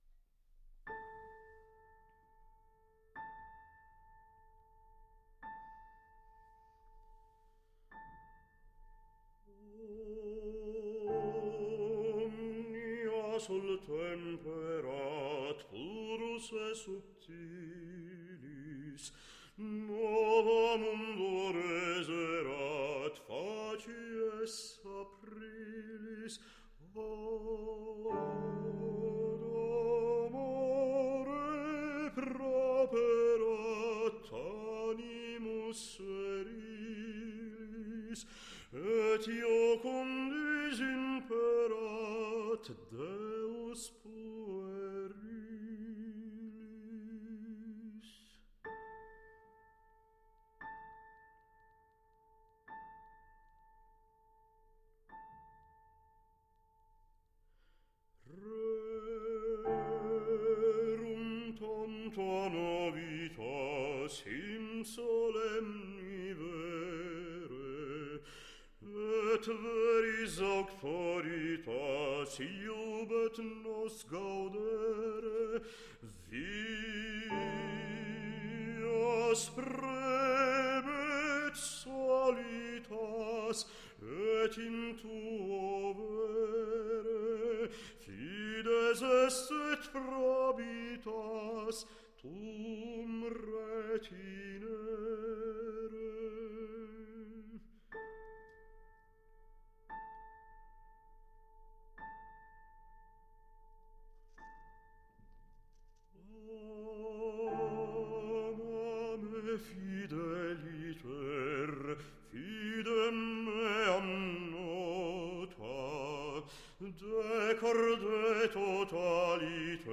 Baritono